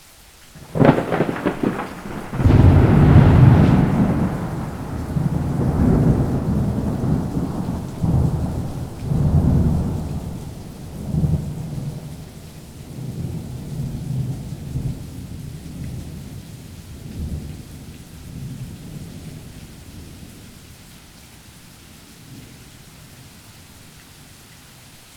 enviro_thunder_2.wav